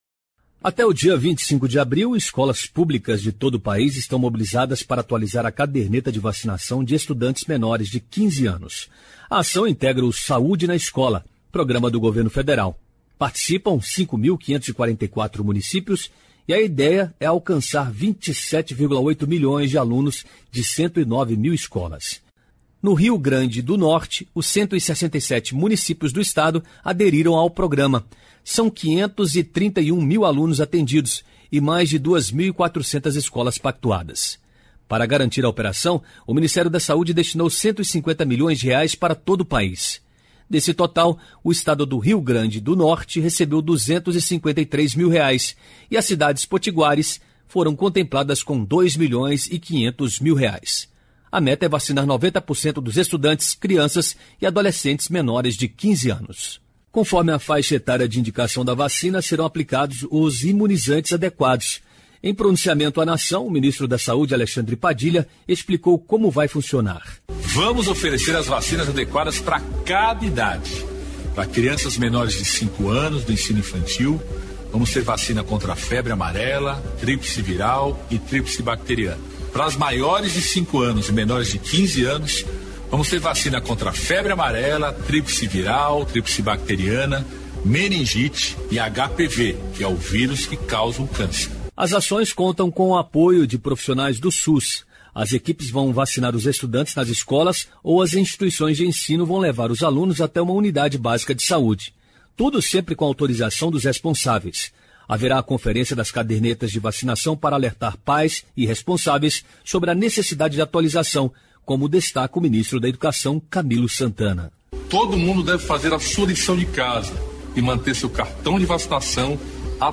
A reportagem especial “Do sertão ao mar” narra como a Barragem de Oiticica, no Seridó potiguar, transformou a vida de milhares de famílias atingidas pela seca e pelo avanço da desertificação. Iniciada em 2013, a obra deslocou cerca de 4 mil pessoas, mas também deu origem à Nova Barra de Santana, um reassentamento planejado com infraestrutura urbana, comércio, escolas e saneamento.